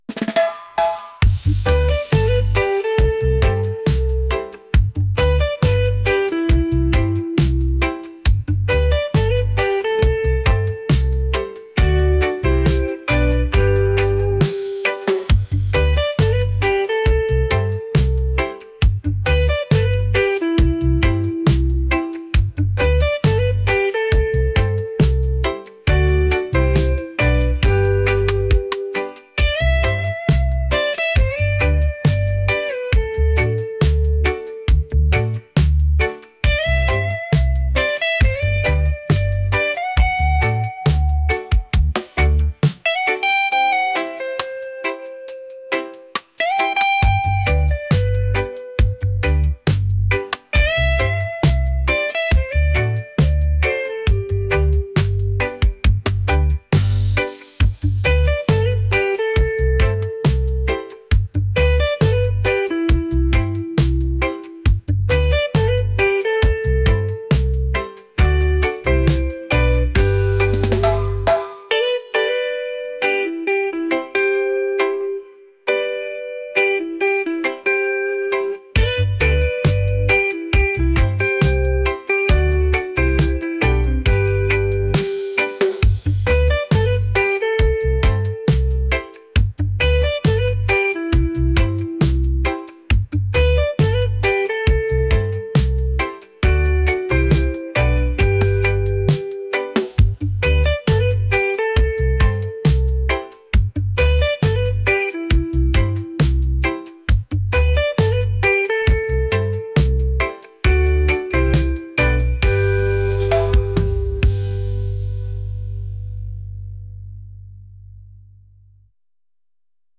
reggae | island | vibes